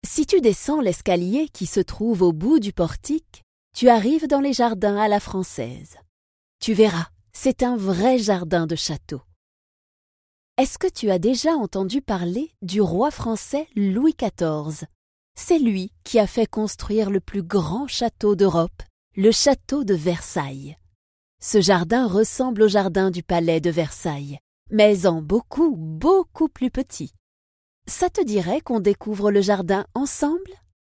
Tief, Verspielt, Vielseitig, Sanft, Corporate
Audioguide